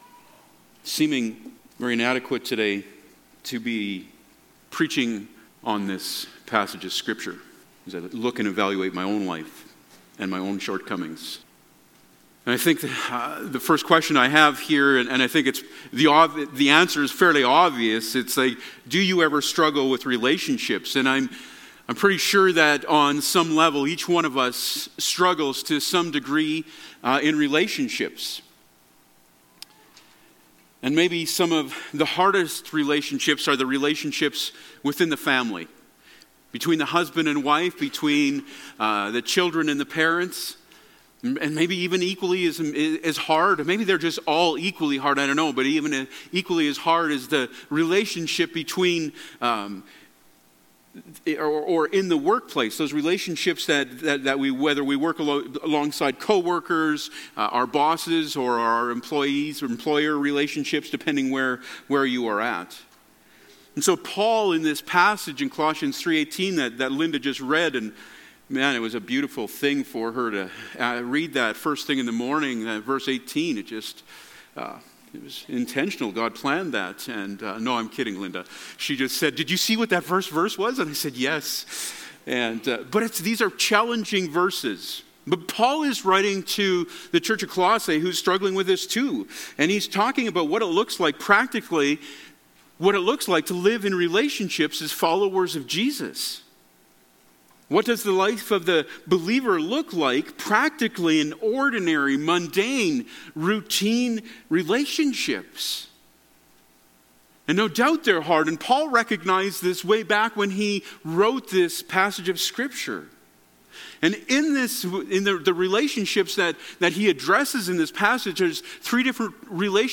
Passage: Colossians 3:18-4:1 Service Type: Sunday Morning